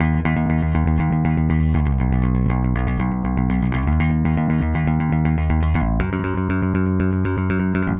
Power Pop Punk Bass 02a.wav